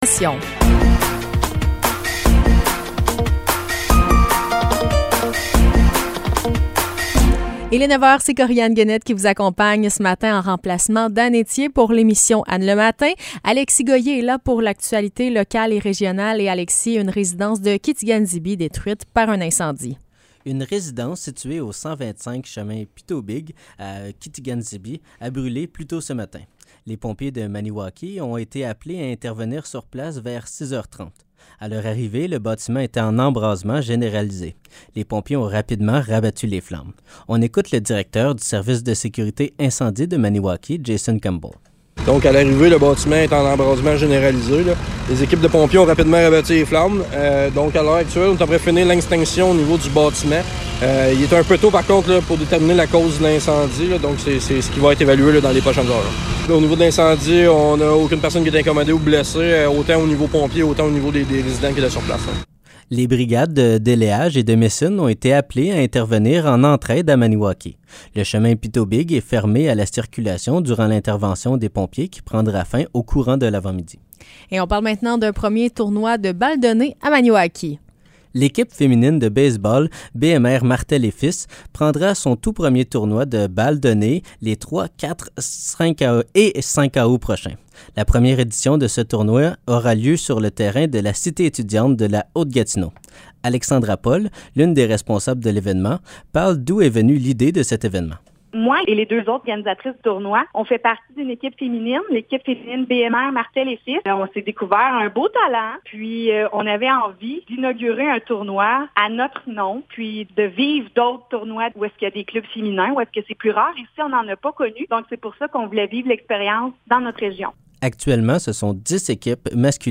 Nouvelles locales - 22 juin 2023 - 9 h